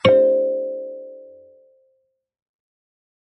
kalimba2_wood-C4-mf.wav